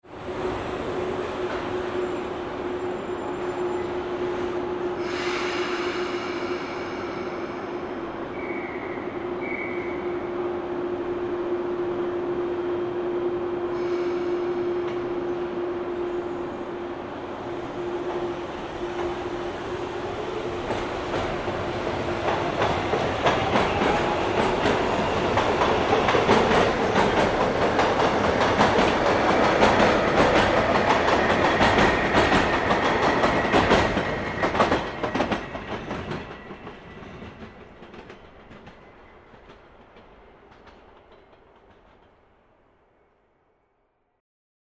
Звук уходящего поезда с открытой платформы метро